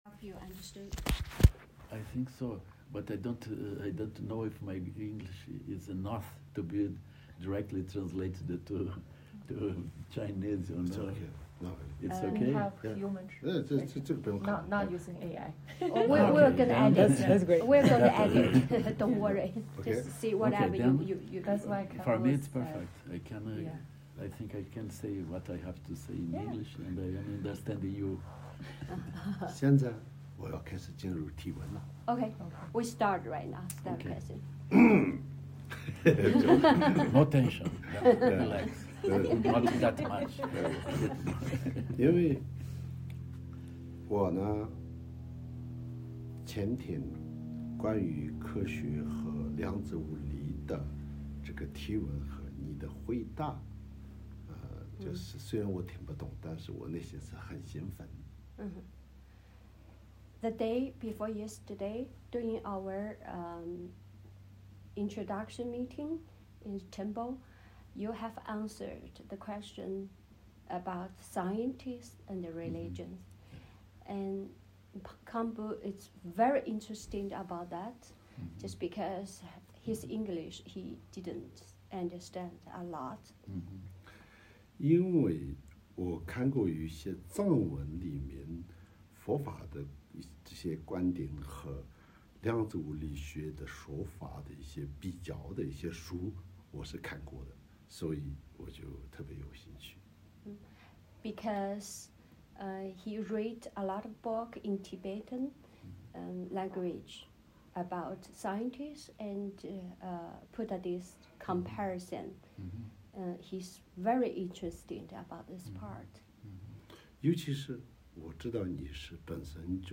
O encontro aconteceu na Estupa de Arya Tara, no dia 16 de março de 2026.